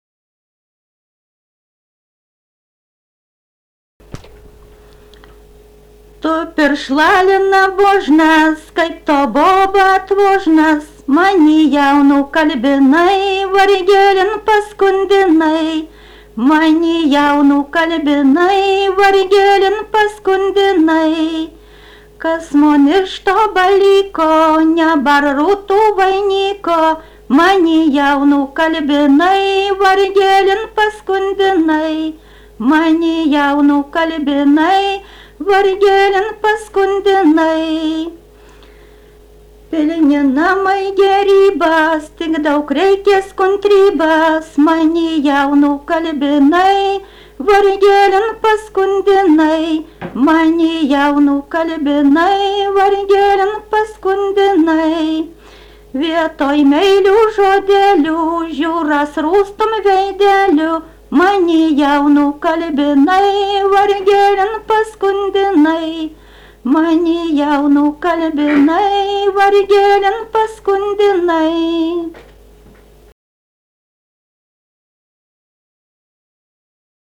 daina, vestuvių
Šimonys
vokalinis